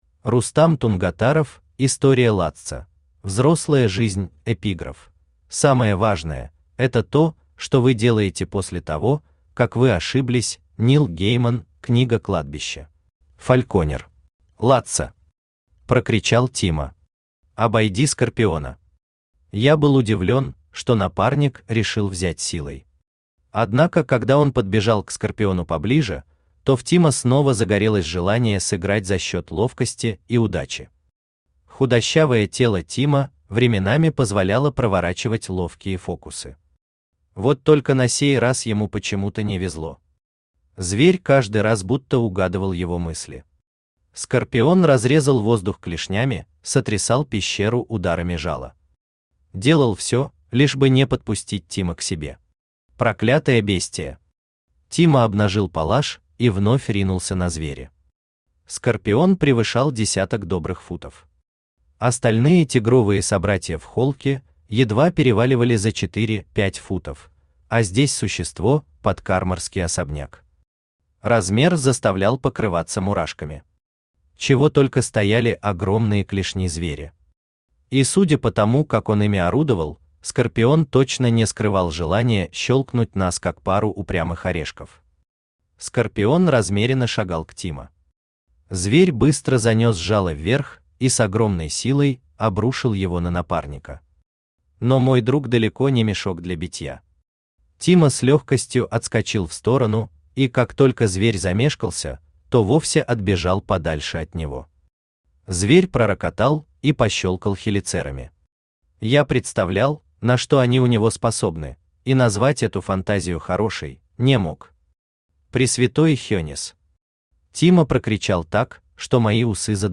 Аудиокнига История Лаццо: Взрослая жизнь | Библиотека аудиокниг
Aудиокнига История Лаццо: Взрослая жизнь Автор Рустам Тунгатаров Читает аудиокнигу Авточтец ЛитРес.